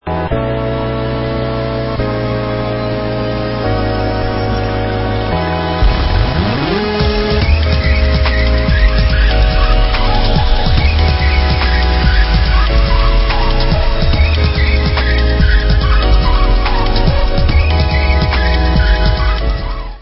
sledovat novinky v oddělení Dance/Breakbeats